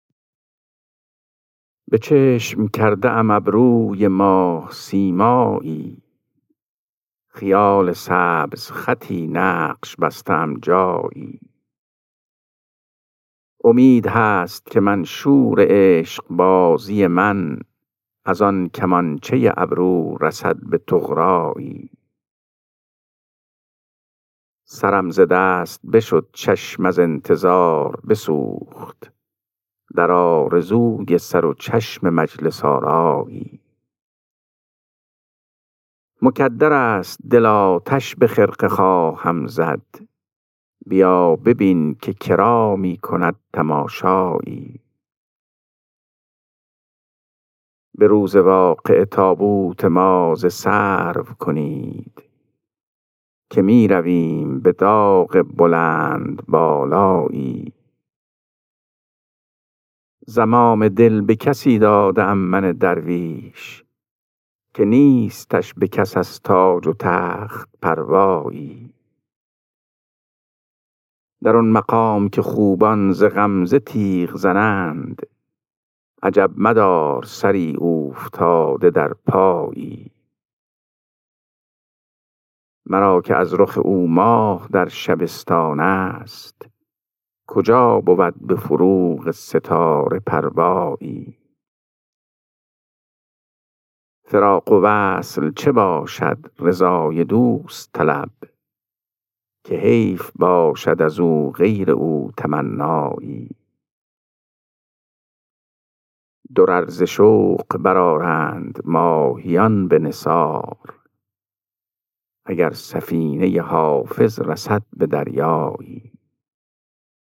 خوانش غزل شماره 491 دیوان حافظ